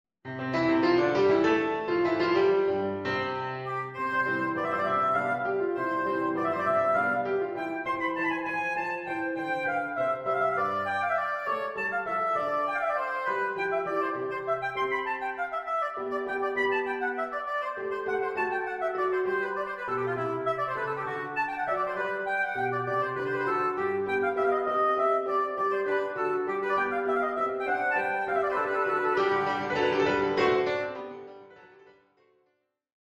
Oboe & Piano
Oboe with piano reduction.